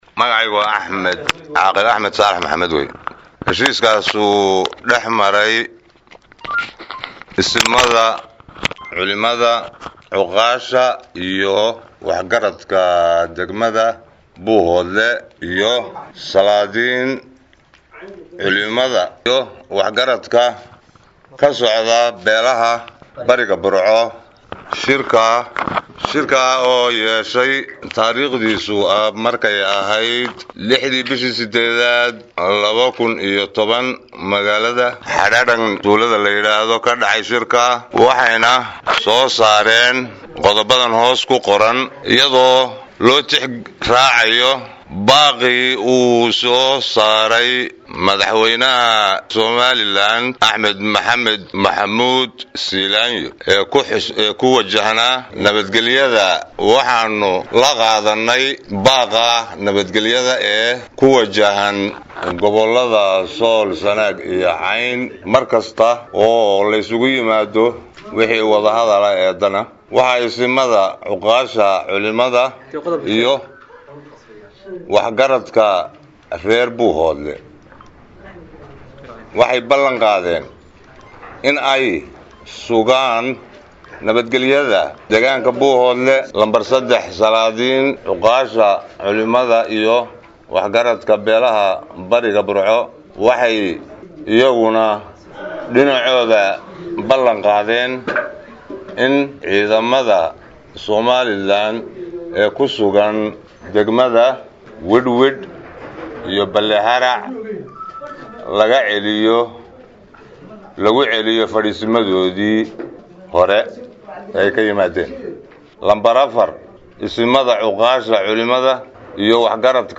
Go'aamadii Shirkii Xadhadhanka oo uu Saxaafada u akhrinayo